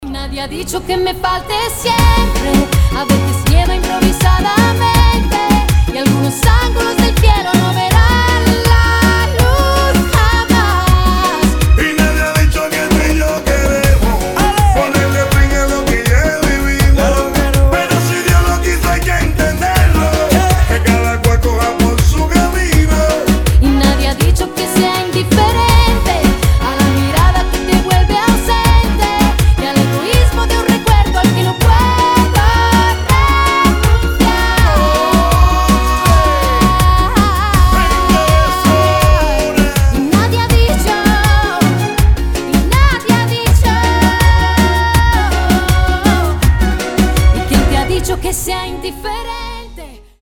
• Качество: 320, Stereo
дуэт
Latin Pop